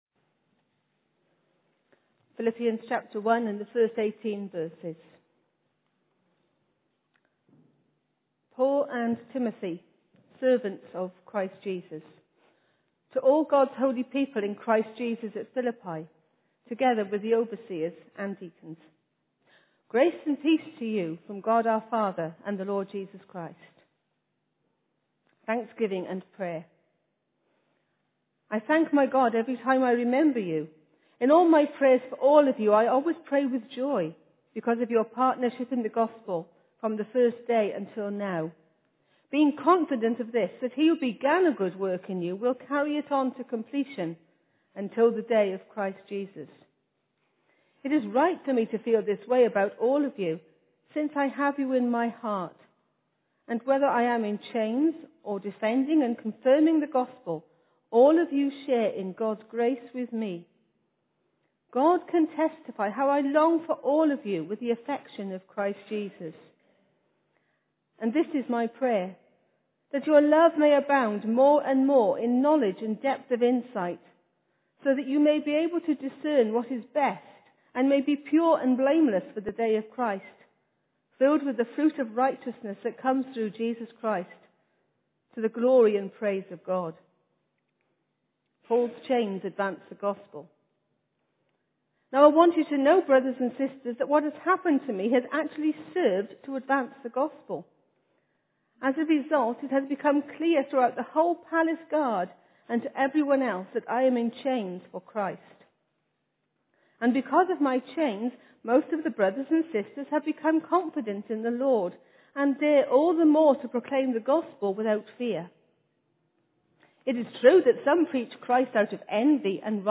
Genre: Speech.